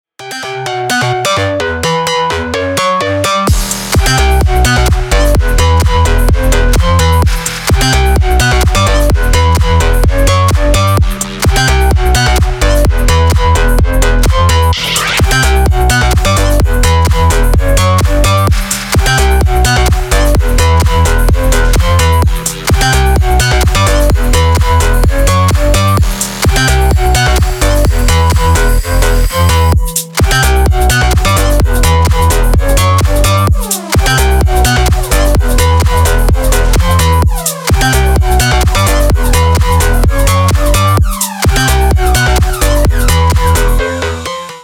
• Качество: 320, Stereo
громкие
жесткие
remix
Electronic
электронная музыка
без слов
future house